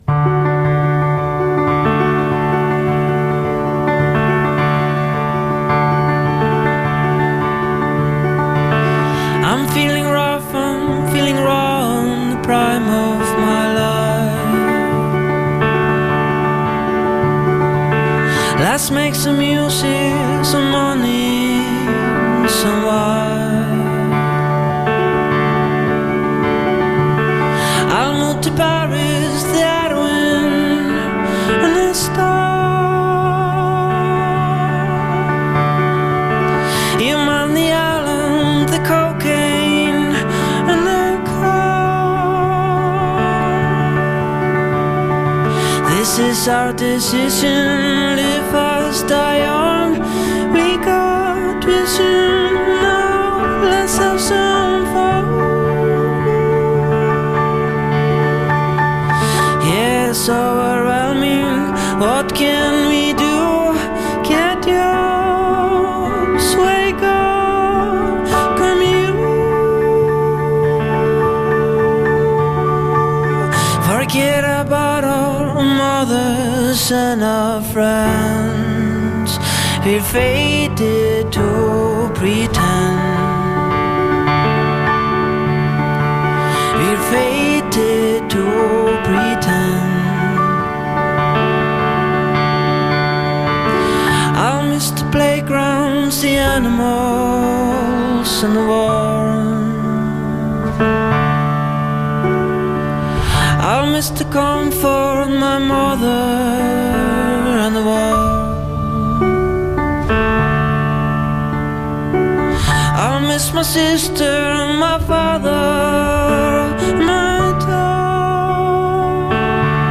dreamily